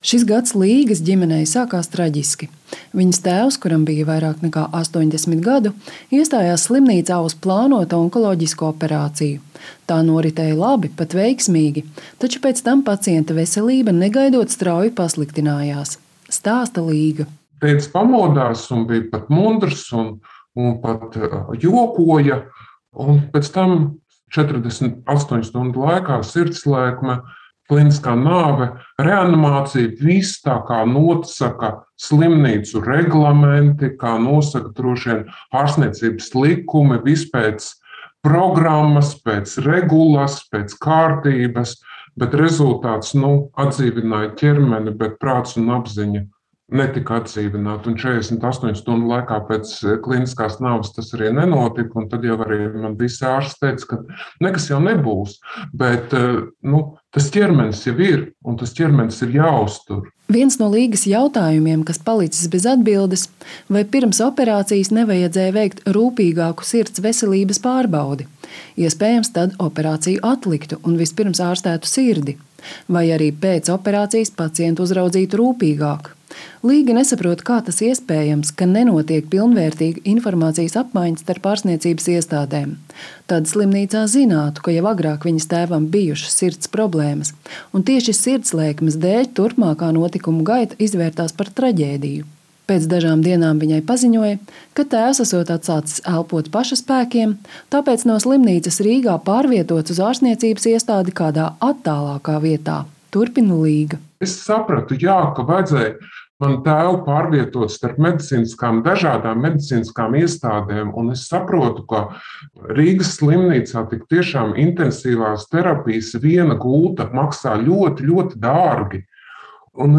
Sieviete, kuras vārdu un balsi pēc viņas lūguma esam mainījuši